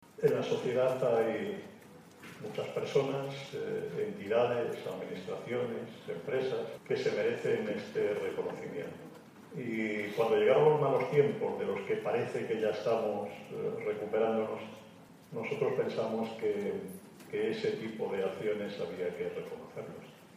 Sevilla y Valencia sirven de escenarios a sendas galas de entrega de premios junto a las primeras autoridades autonómicas y locales y la sociedad civil